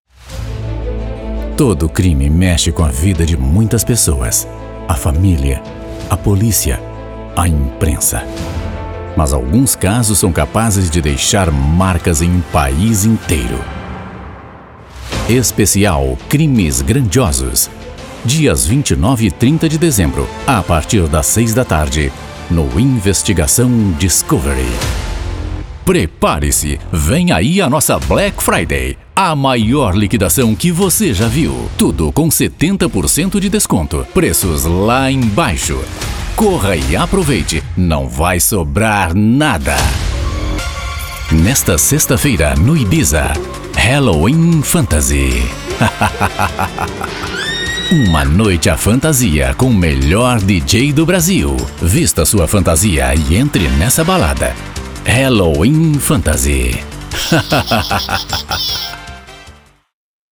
Trailers de películas
Mi voz es profunda, amigable, natural y conversacional.
Trabajo desde mi propio estudio profesionalmente equipado y con tratamiento acústico.